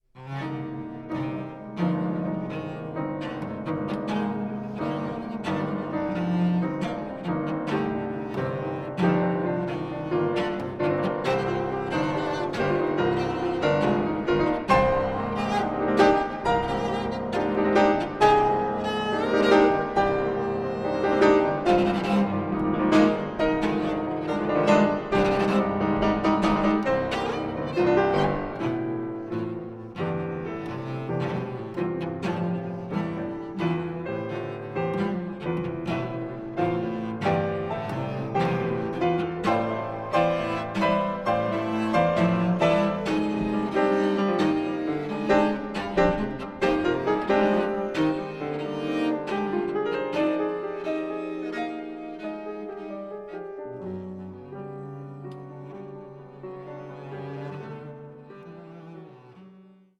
Cello
Klavier